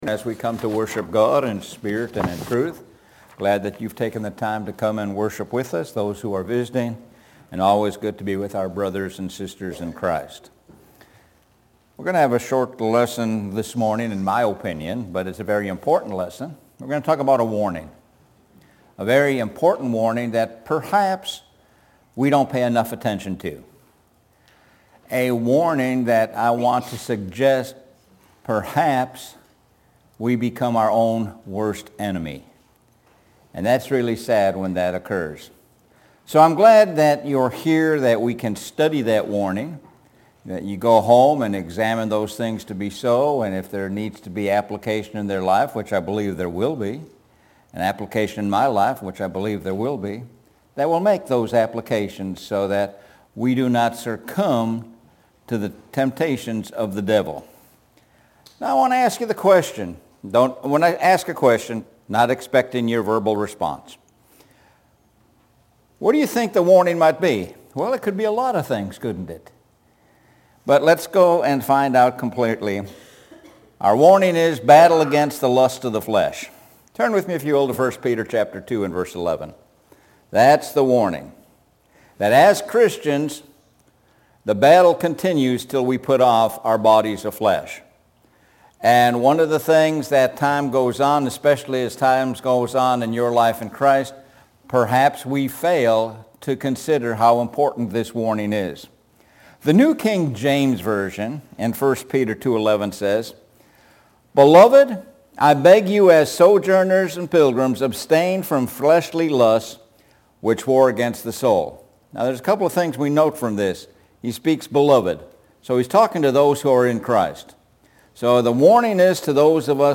Sun AM Sermon – Lust of the Flesh